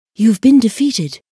1 channel
youdefeated.wav